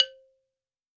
52-prc03-bala-c3.wav